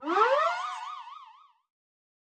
Media:Medic_evo2_dep.wav 部署音效 dep 在角色详情页面点击初级、经典、高手和顶尖形态选项卡触发的音效